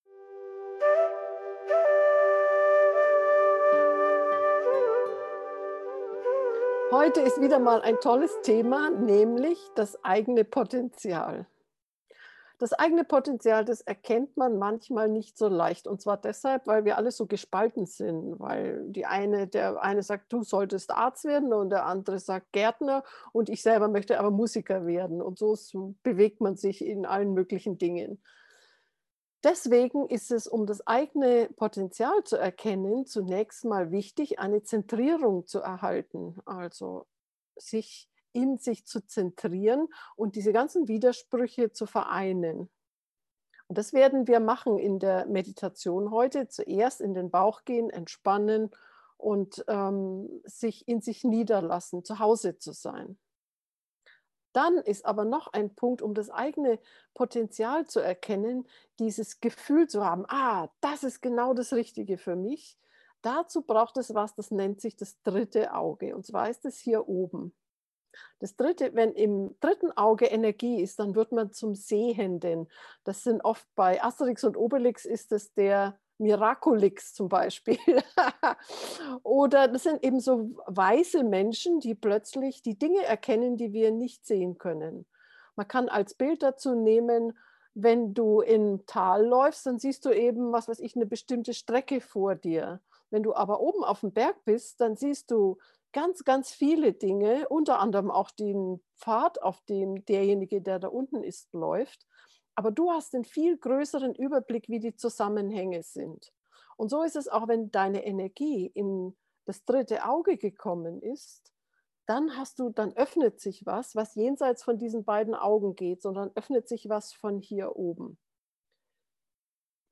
Geführte Meditationen Folge 61: Das eigene Potenzial erkennen Play Episode Pause Episode Mute/Unmute Episode Rewind 10 Seconds 1x Fast Forward 30 seconds 00:00 / 16:50 Abonnieren Teilen RSS Feed Teilen Link Embed
das-eigene-potenzial-drittes-auge-meditation.mp3